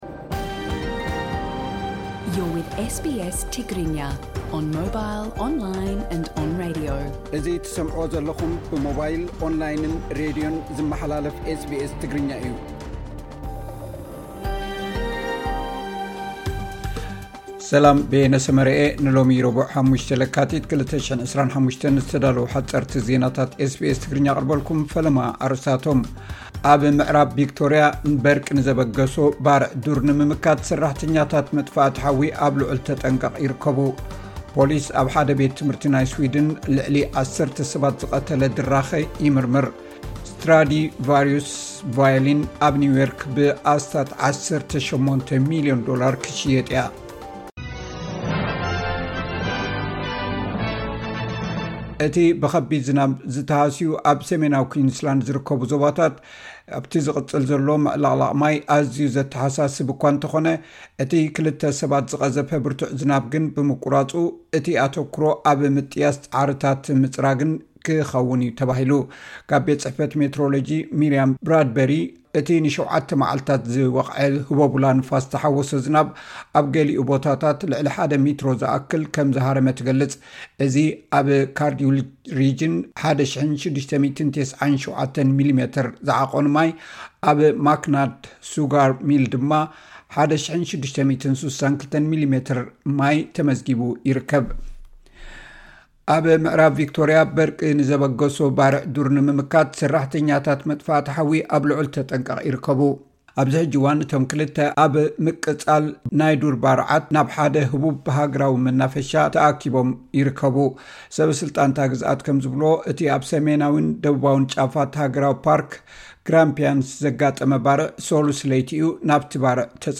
ዕለታዊ ዜና ኤስ ቢ ኤስ ትግርኛ (05 ለካቲት 2025)